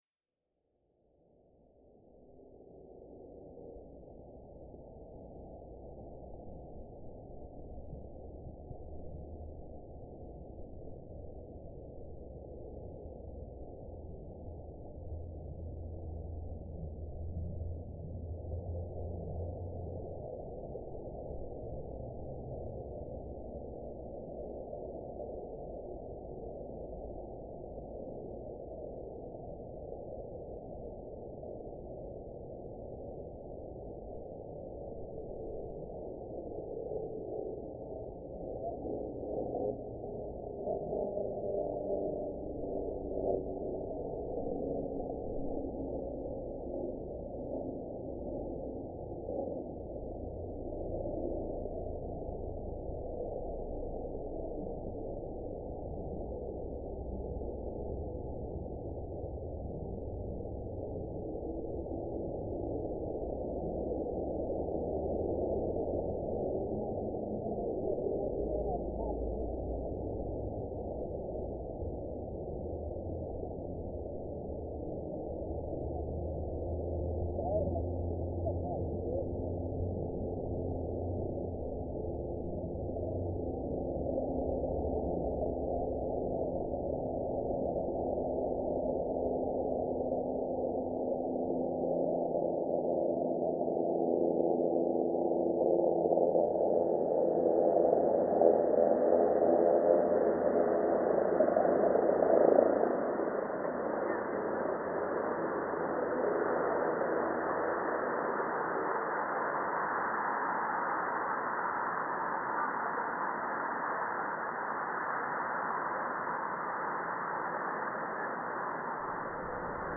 A High Line ambience